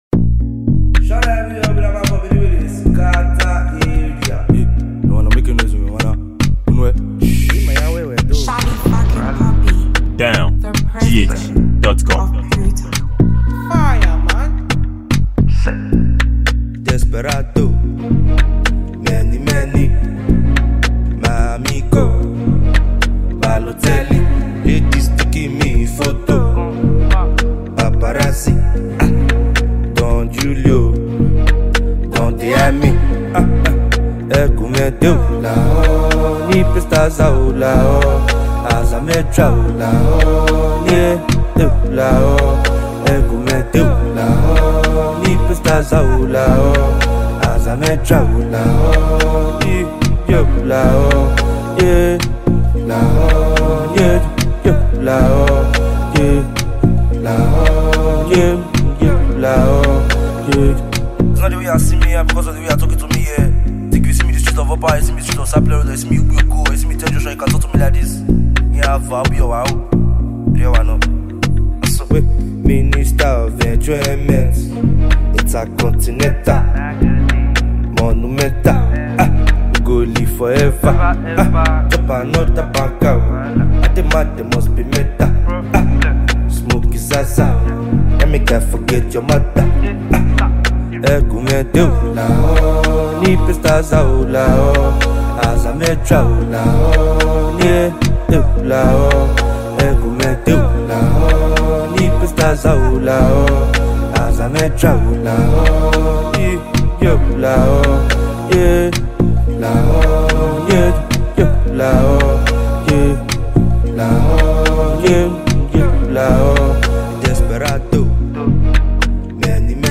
Multi-talented Nigerian songwriter and recording artist.
a captivating afrobeat mp3 for download.